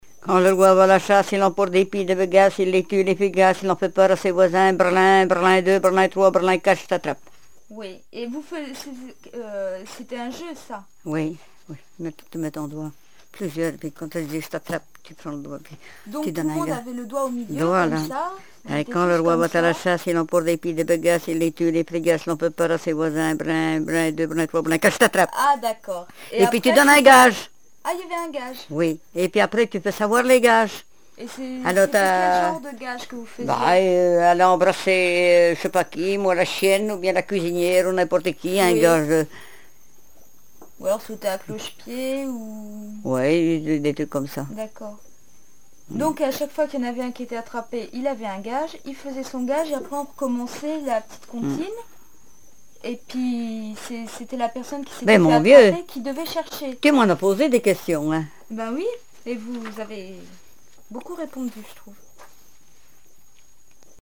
Enfantines - rondes et jeux
formulette enfantine
Répertoire de chansons traditionnelles et populaires
Pièce musicale inédite